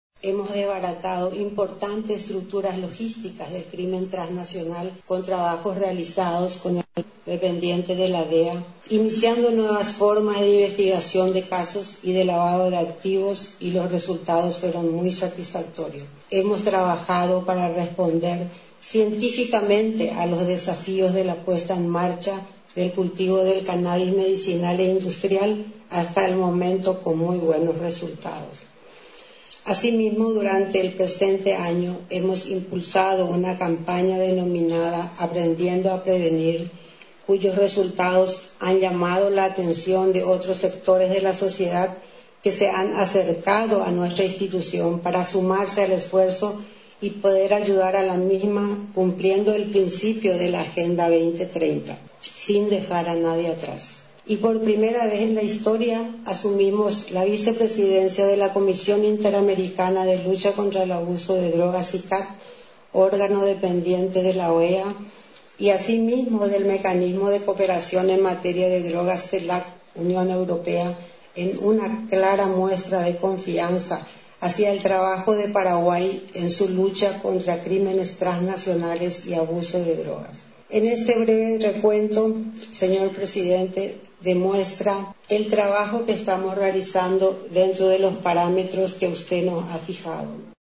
Unos 32 agentes especiales de la Secretaría Nacional Antidrogas egresaron este lunes, en un acto realizado en el teatro «Tom Jobim» de la embajada de Brasil, que contó con la presencia del presidente de la República, Mario Abdo Benítez.
La ministra Zully Rolón, titular de la Senad, exhortó a los egresados a tener dedicación plena y con dignidad, al servir a la Patria.